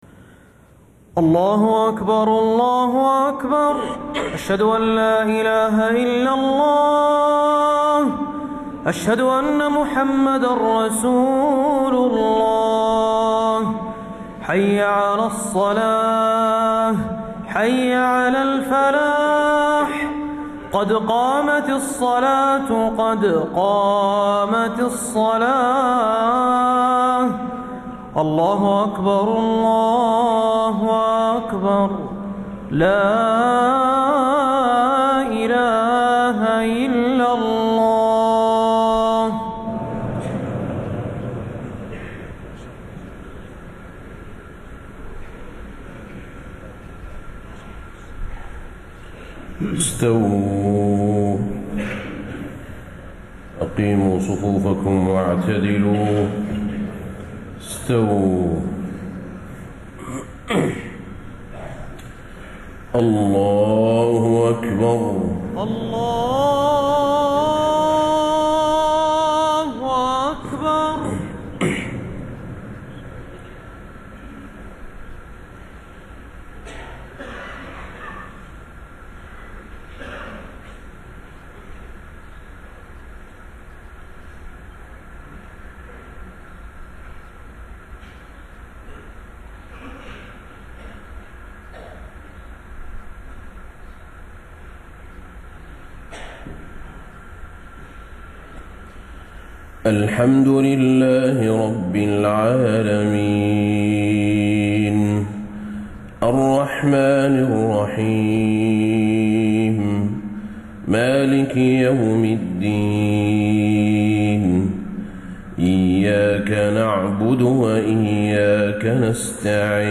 صلاة الفجر 3-6-1435 ما تيسر من سورة الأنبياء > 1435 🕌 > الفروض - تلاوات الحرمين